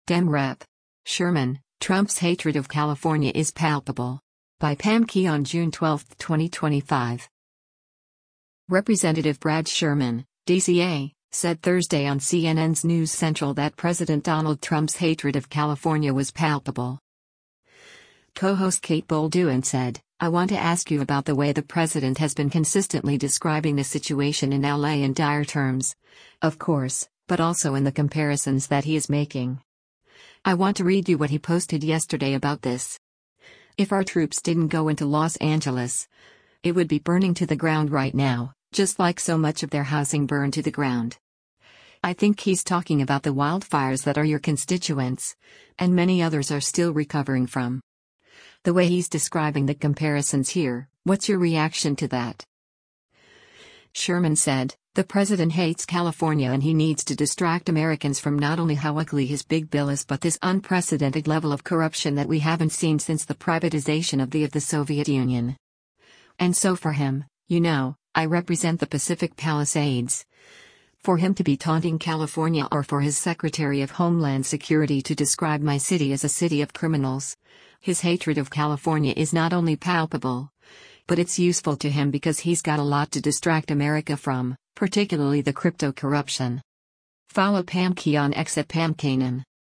Representative Brad Sherman (D-CA) said Thursday on CNN’s “News Central” that President Donald Trump’s “hatred” of California was “palpable.”